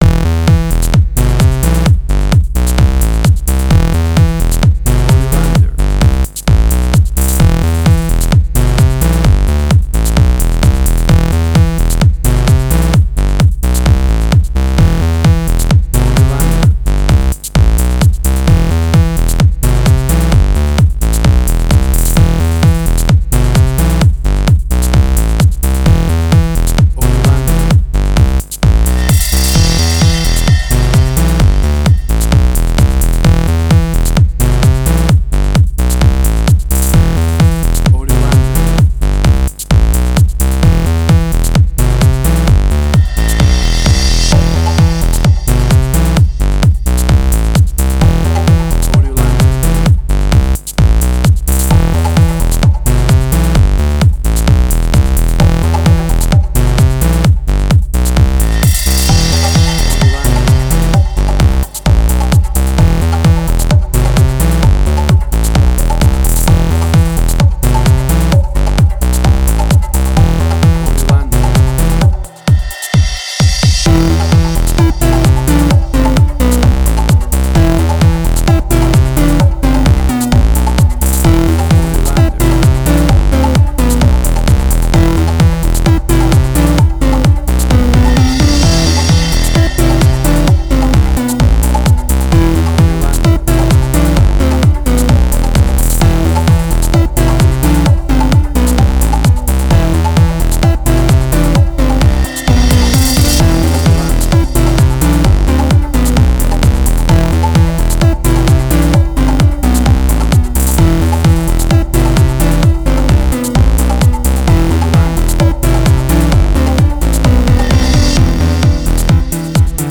House
WAV Sample Rate: 16-Bit stereo, 44.1 kHz
Tempo (BPM): 130